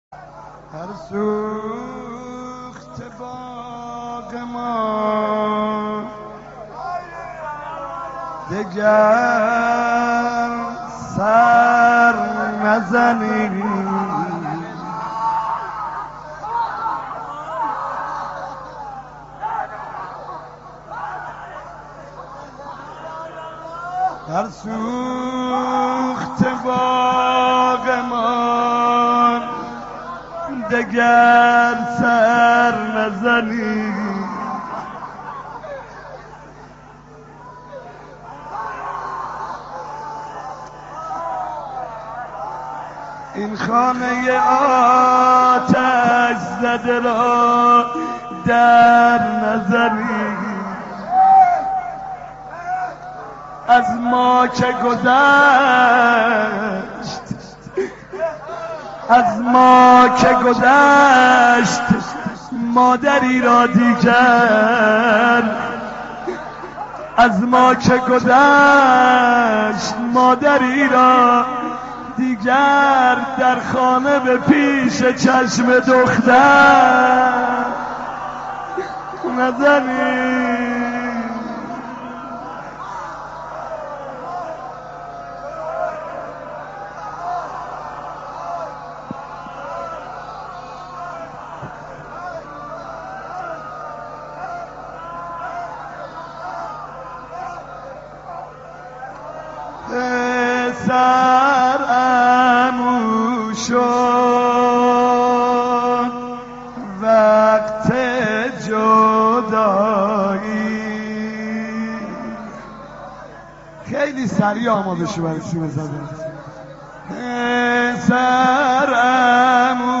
نوحه خوانی در سوگ شهادت همسر مظلومه علی(ع)، حضرت فاطمه(س)